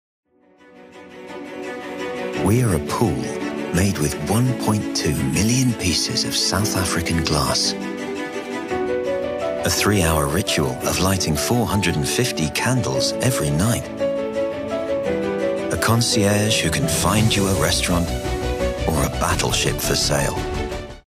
Narration
Microphone à valve à condensateur cardioïde Sontronics Aria
Microphone dynamique SHURE SM7B